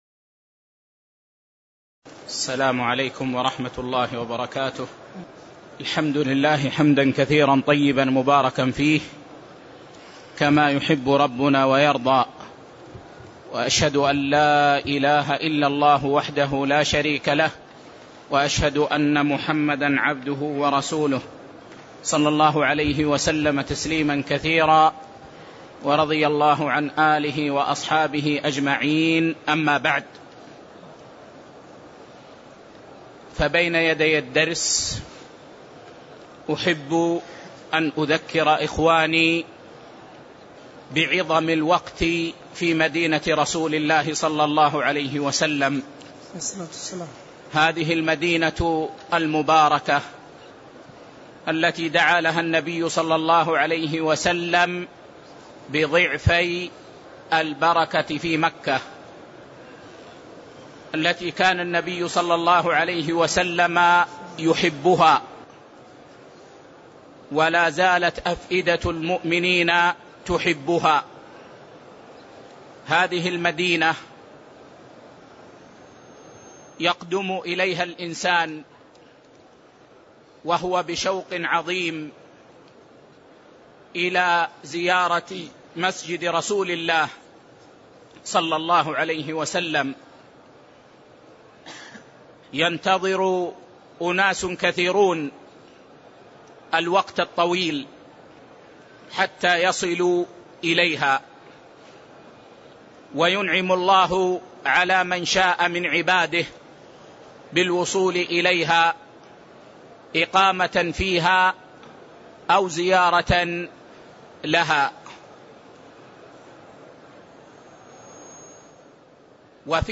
تاريخ النشر ٢٥ ذو القعدة ١٤٣٤ هـ المكان: المسجد النبوي الشيخ